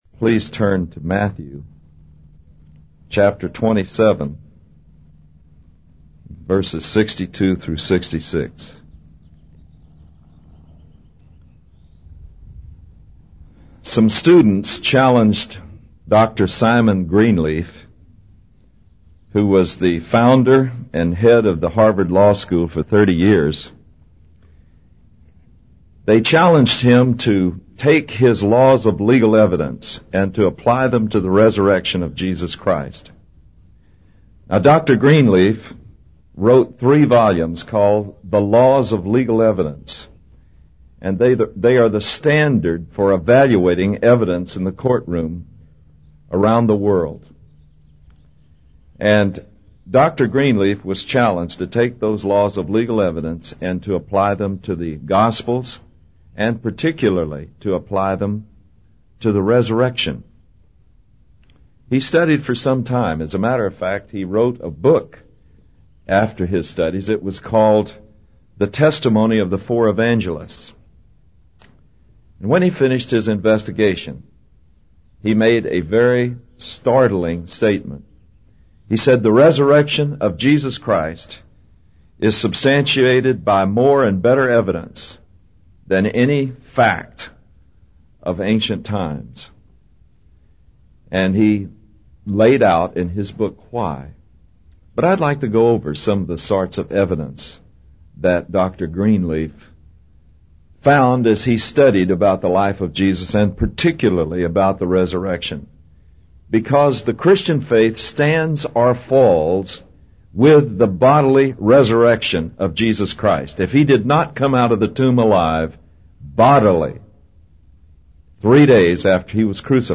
In this sermon, the speaker discusses how Dr. Simon Greenleaf, the founder of Harvard Law School, applied his laws of legal evidence to the resurrection of Jesus Christ.